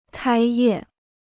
开业 (kāi yè) Free Chinese Dictionary